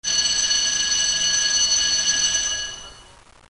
Allarme antincendio
Suono di allarme campanello antincendio.
ALARM2.mp3